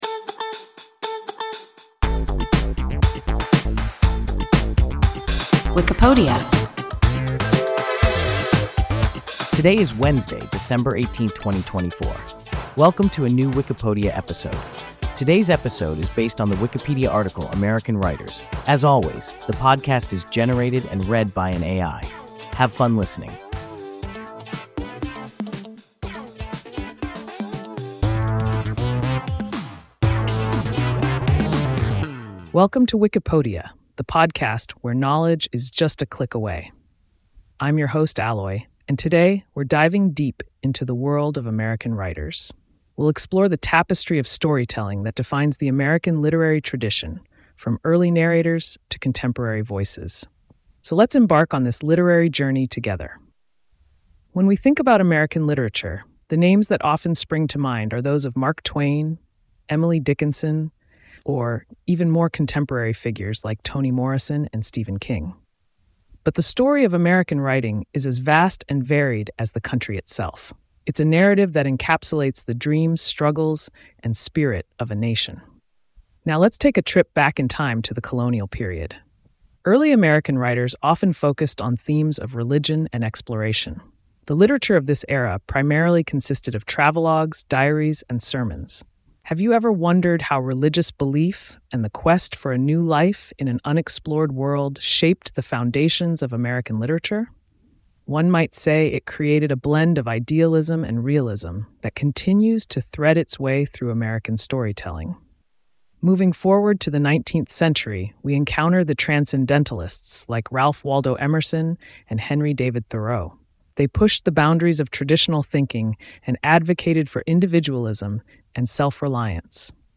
American Writers – WIKIPODIA – ein KI Podcast